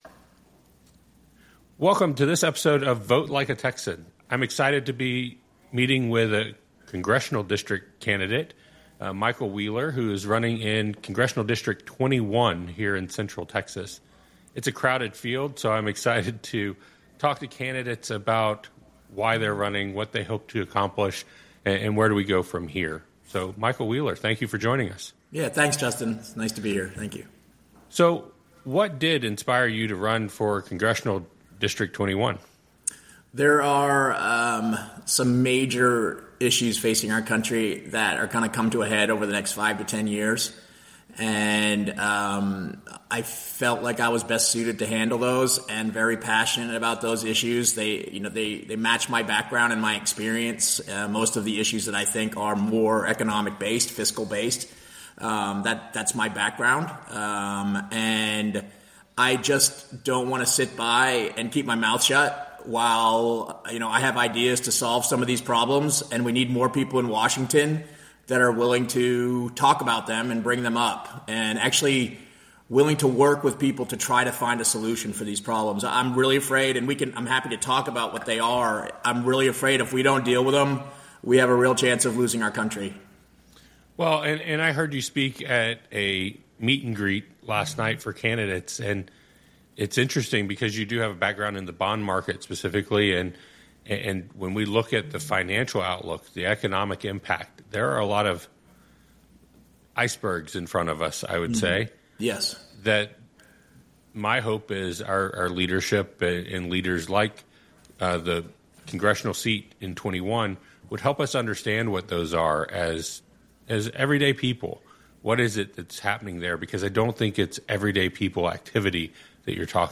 issue-focused conversation